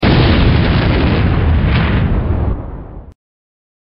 歷來法會或年節團聚前，臥雲山都會響起震天撼地的雷鳴聲。